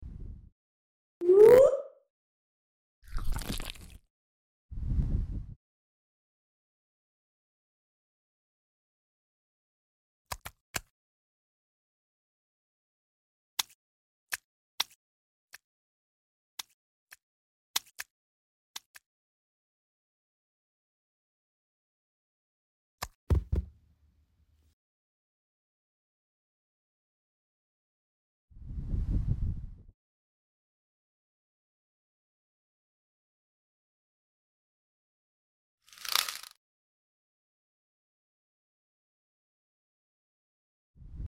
WOOP! sound effects free download